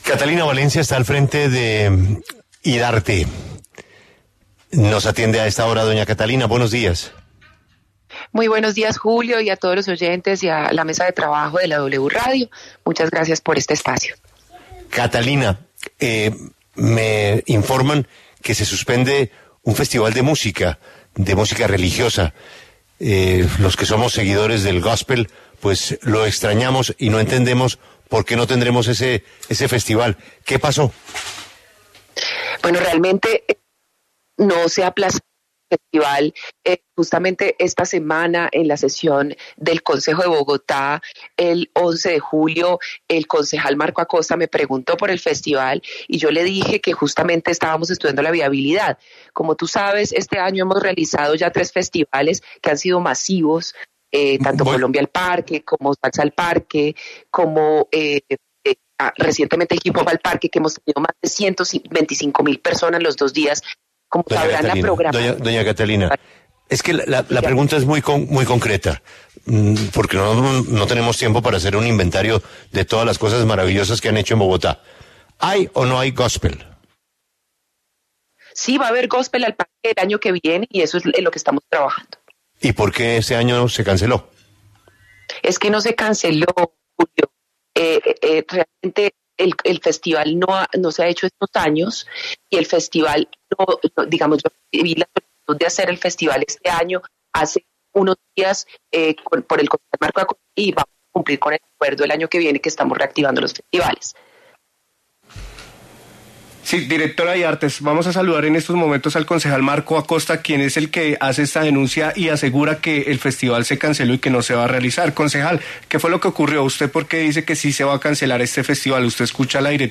La directora de Idartes pasó por los micrófonos de La W para aclarar que el festival no se ha cancelado y el próximo año los bogotanos podrán disfrutar del evento.